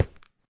Sound / Minecraft / step / stone5.ogg
stone5.ogg